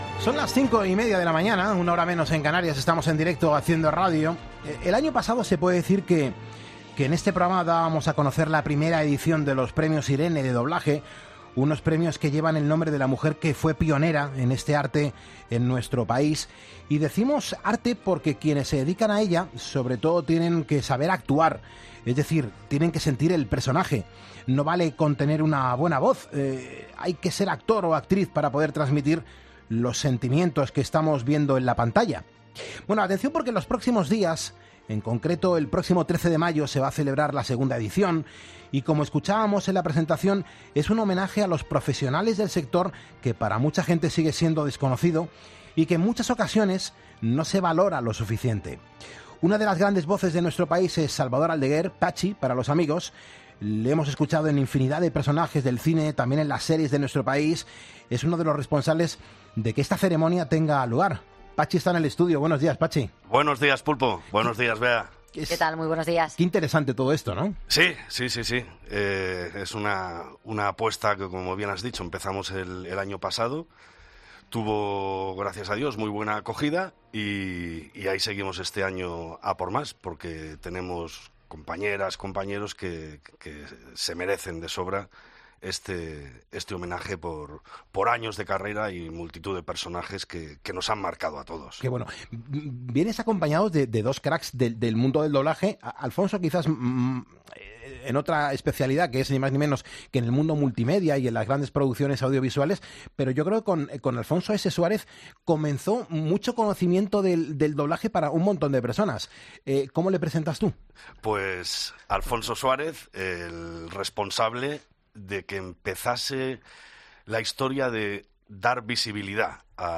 El 13 de mayo se va a celebrar la segunda edición de los Premios Irene de doblaje. En 'Poniendo las Calles' entrevistamos a los intérpretes más destacados